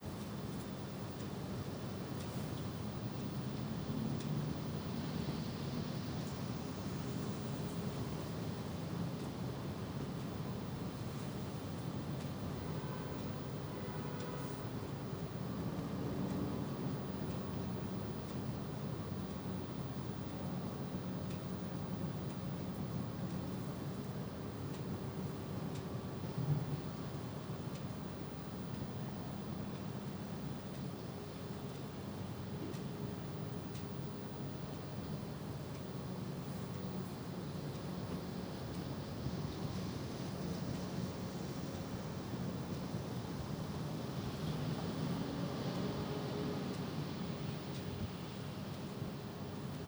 Ambiente interior de habitación en una casa de una ciudad (reloj de fondo)
ambiente
ruido
Sonidos: Hogar
Sonidos: Ciudad